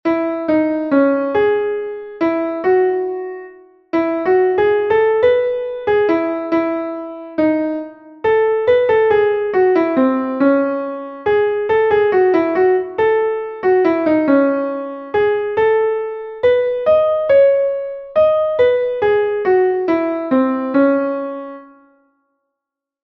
Entoación a capella